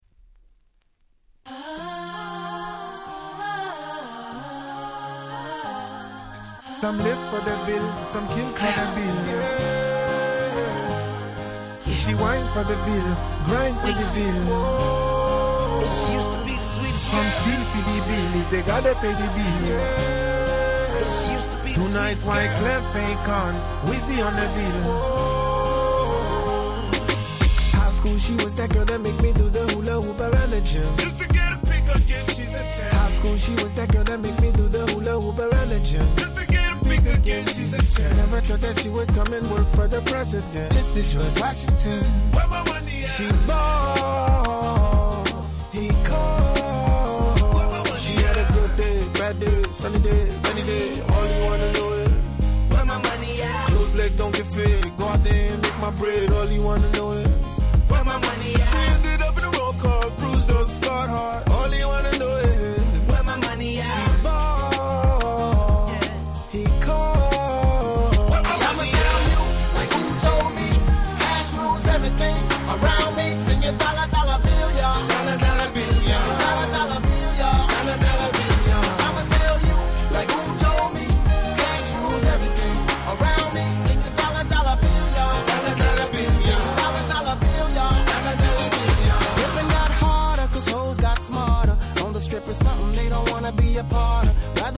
07' Super Hit R&B !!